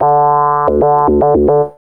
2804L SYNRIF.wav